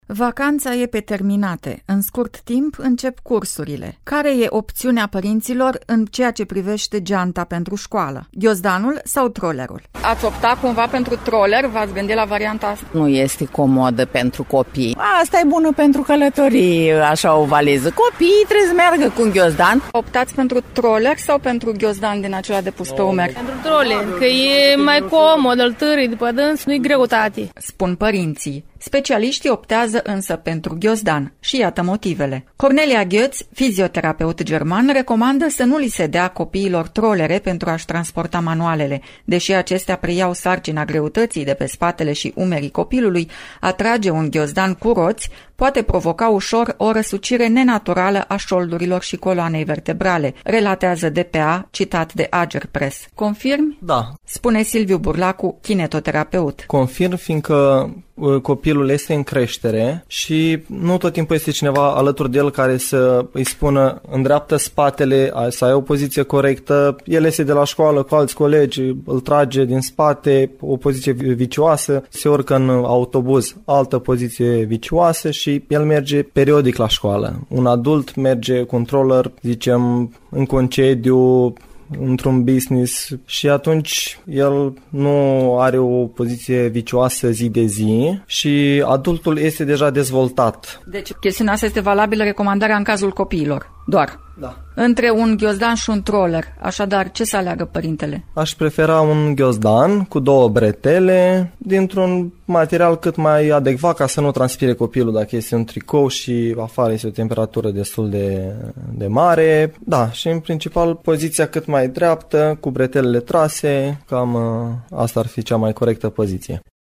Ce recomandă aceştia aflaţi din reportajul următor: